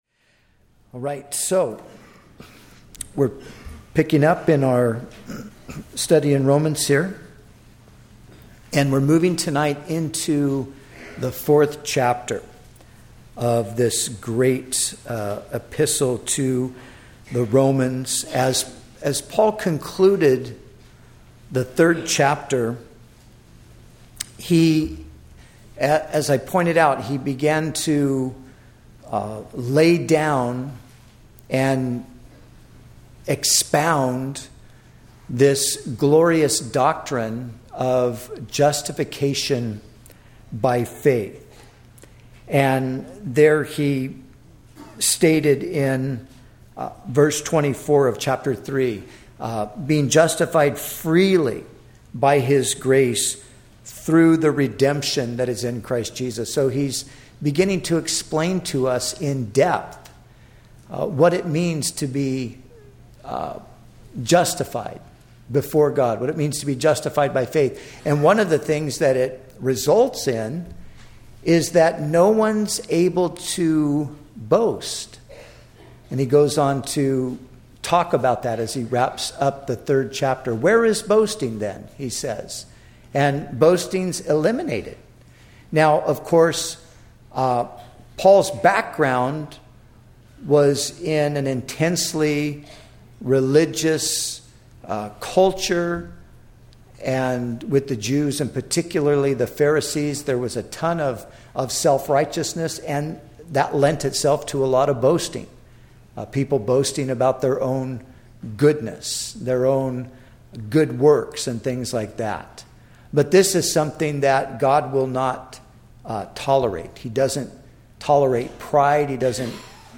In this sermon on Romans, the preacher delves into the doctrine of justification by faith. He emphasizes that through God's grace and the redemption found in Christ Jesus, believers are justified freely.